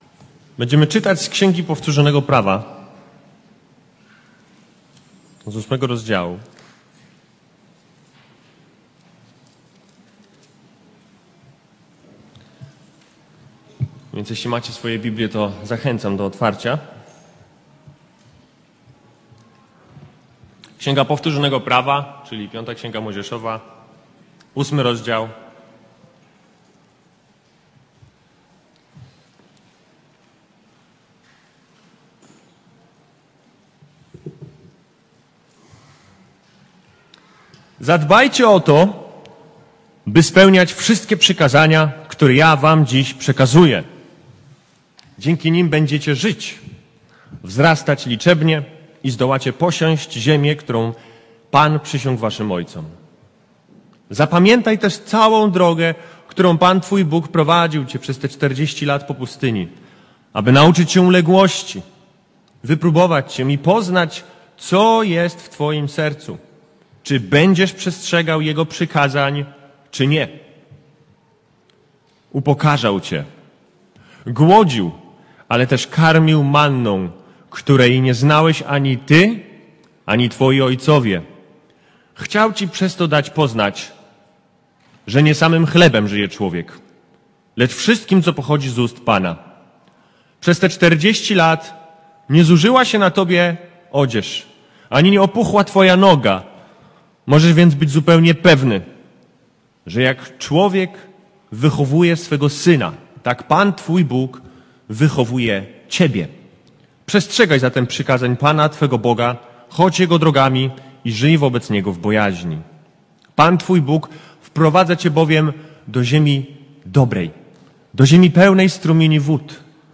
Passage: V księga Mojżeszowa 8, 1-20 Kazanie